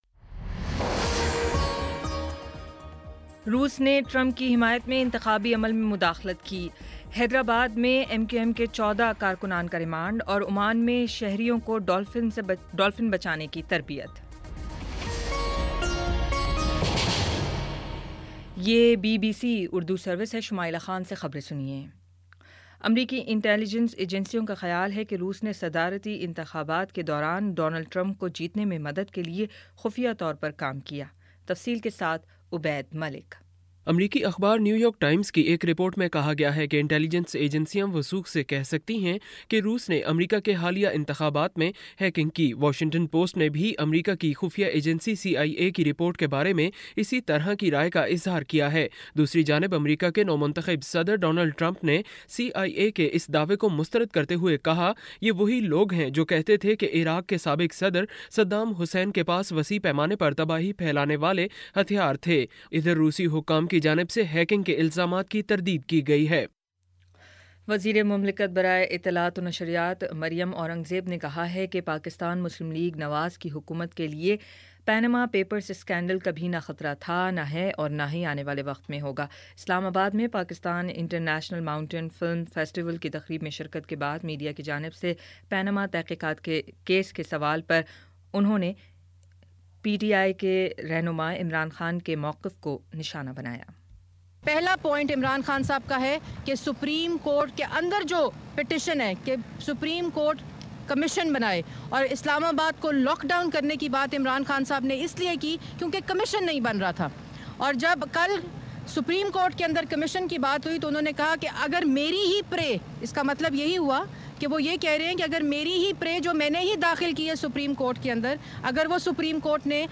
دسمبر 10 : شام پانچ بجے کا نیوز بُلیٹن